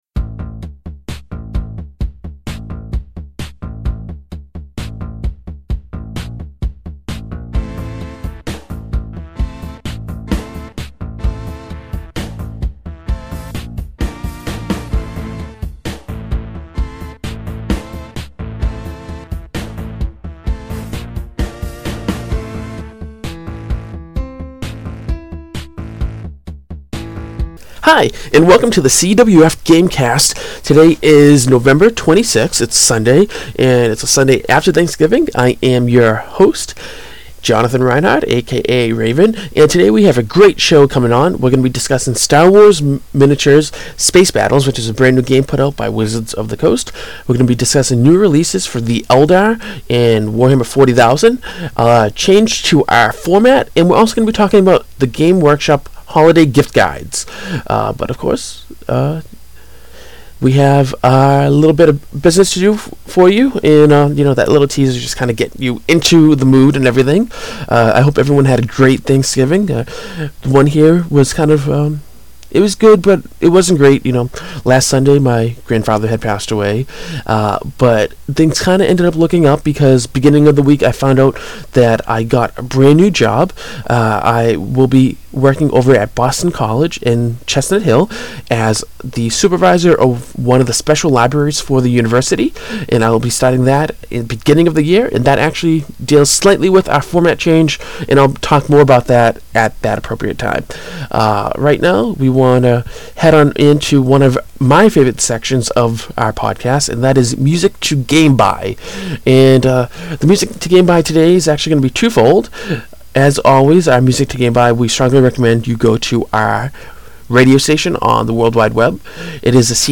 Today we discuss Star Wars Minis Space Battles, GW Eldar New Releases, Format Change, and GW’s Holiday Gift Guides. We also have some tunes for you!